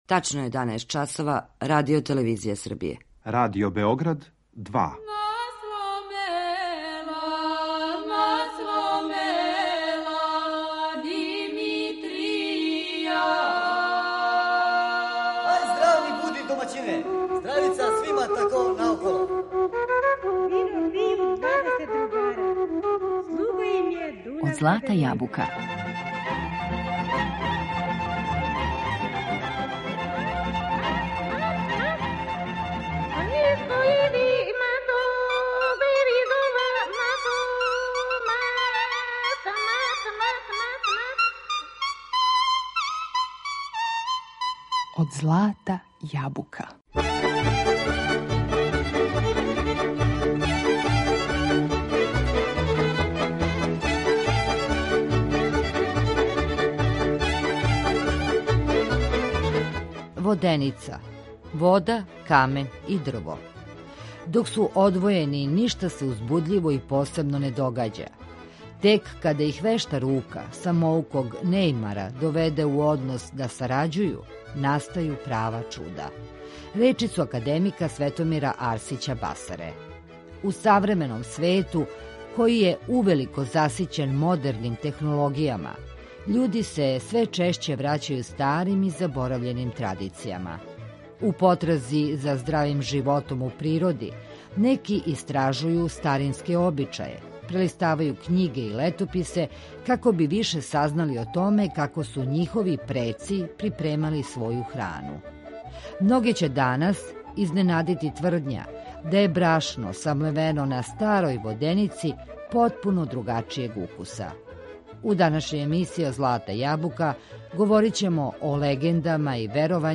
Многе ће данас изненадити тврдња да је брашно самлевено на старој воденици потпуно другачијег укуса. У данашњој емисији Од злата јабука говоримо о легендама и веровањима којима је инспирација била воденица, уз одабрану изворну музику.